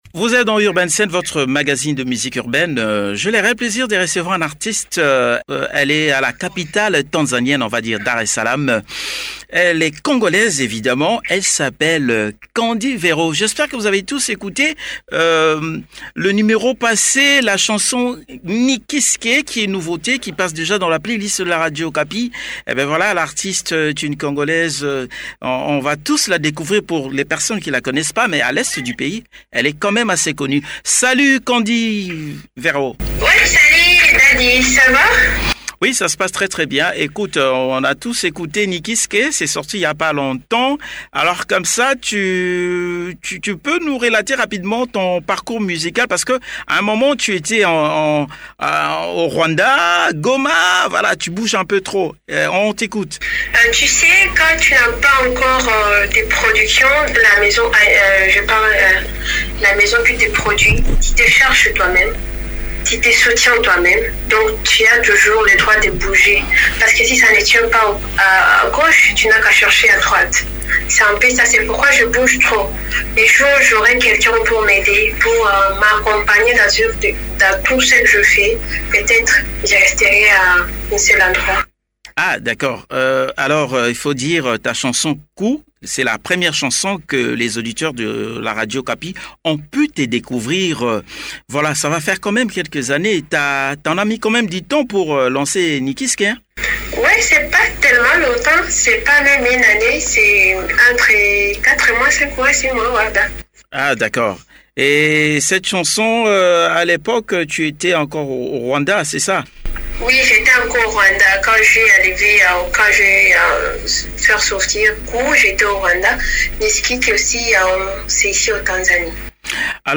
Joint par le biais de son téléphone l'artiste nous dévoile ses projets.